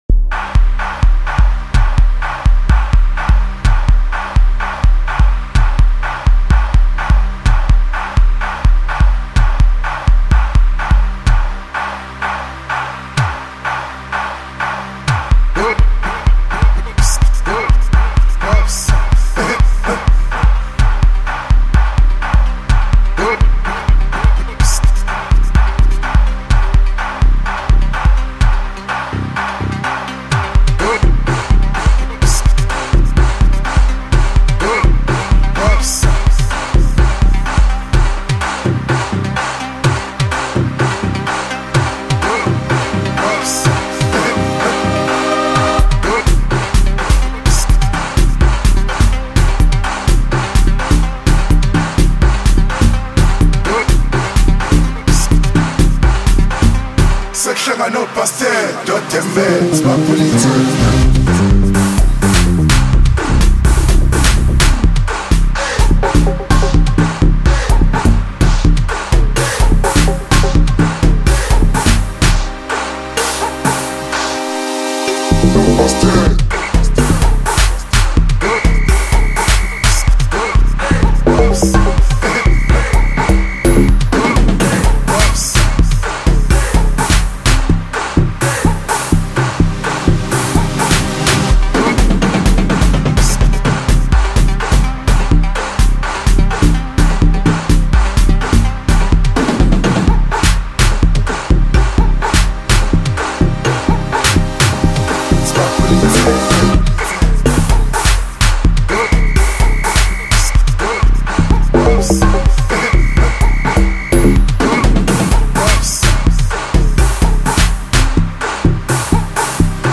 Home » Amapiano » DJ Mix » Hip Hop
South African singer-songwriter